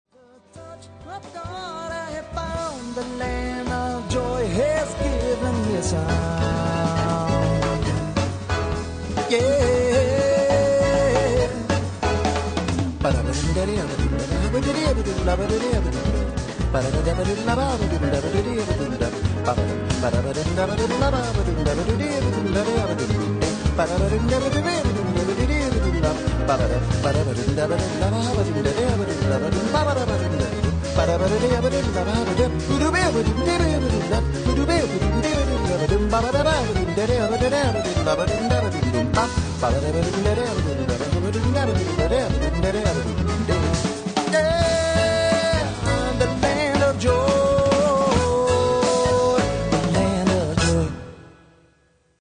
Ollon du Jazz